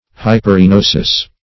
Search Result for " hyperinosis" : The Collaborative International Dictionary of English v.0.48: Hyperinosis \Hy`per*i*no"sis\, n. [NL., fr. Gr.